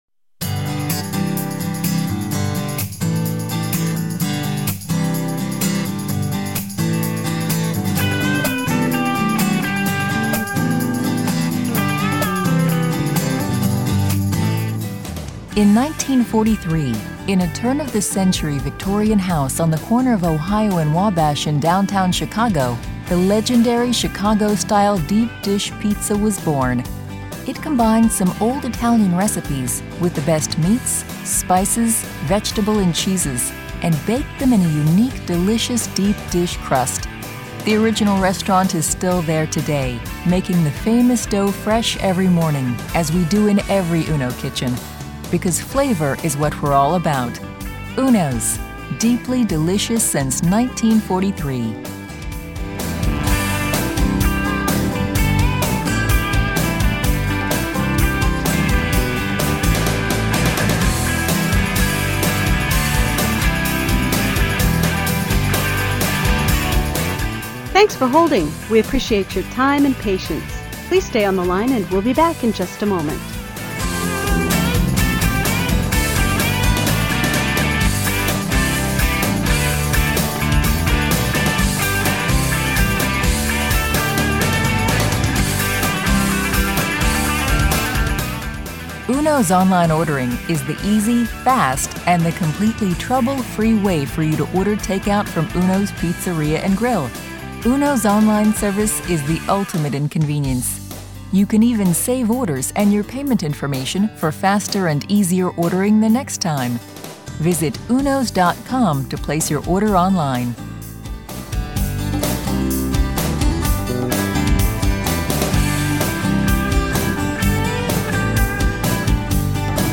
Music On Hold